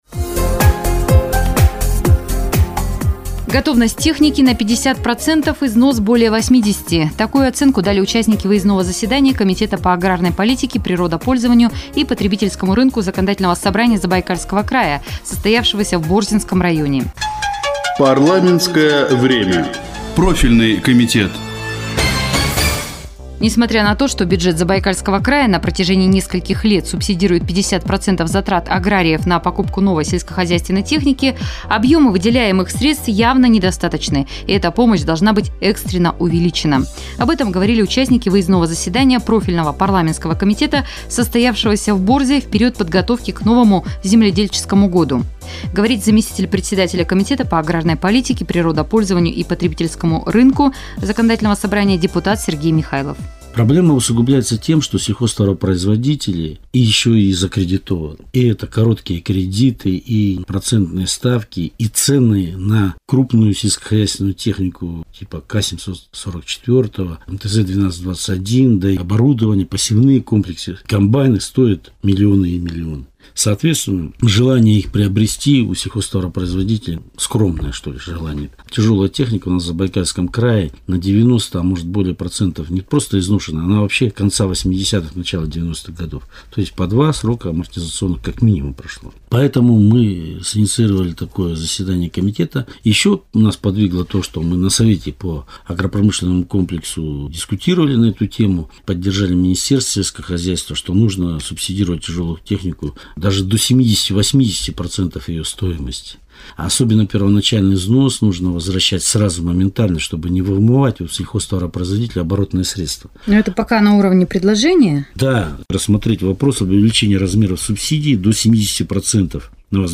Информационный сюжет "Посевной сезон-2013" (Радио России - Чита)
Заместитель председателя комитета по аграрной политике Сергей Михайлов комментирует итоги выездного заседания в Борзинский район